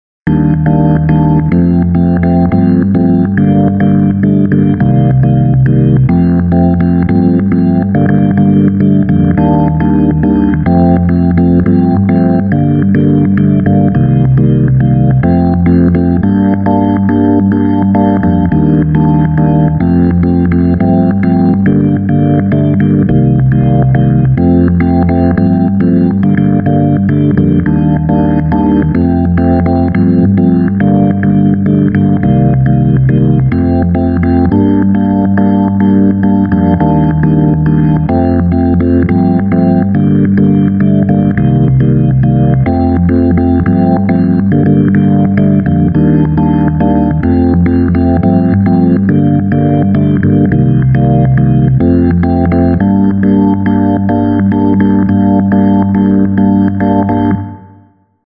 Blues Split 02